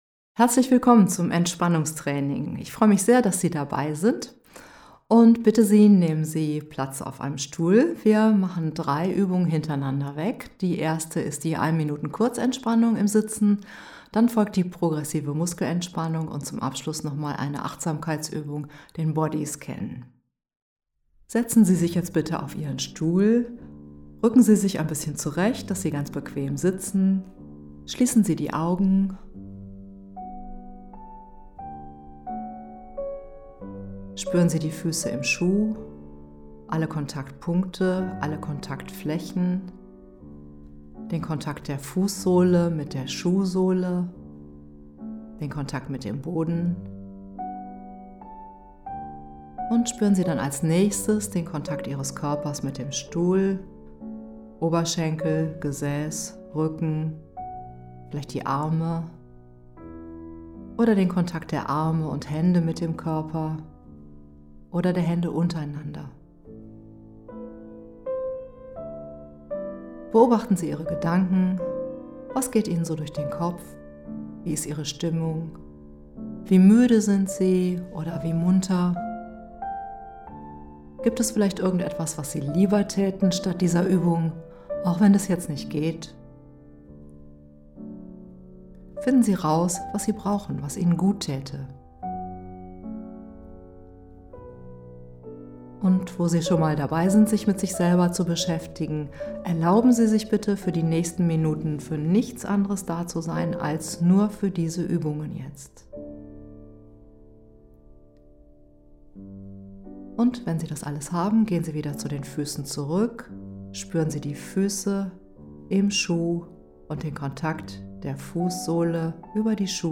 Entspannungsübung anhören
Entspannungsuebung.mp3